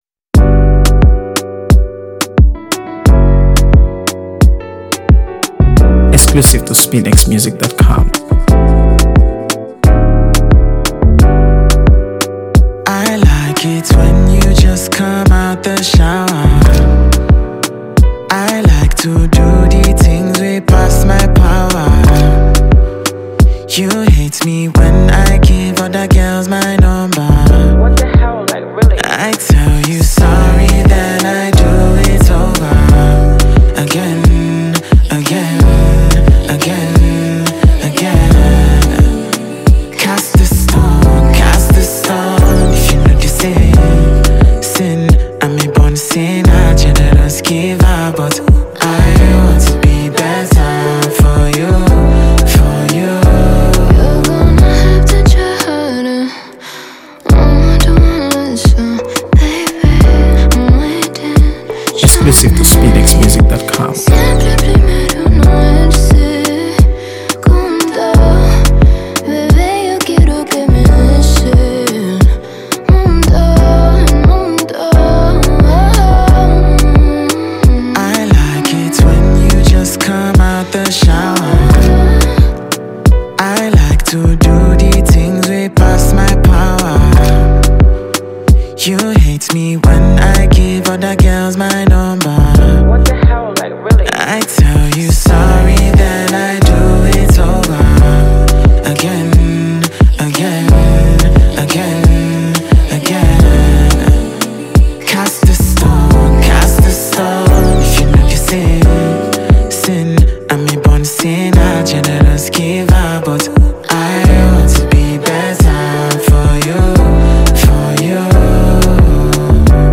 AfroBeats | AfroBeats songs
It’s smooth, it’s honest, and it belongs on your playlist.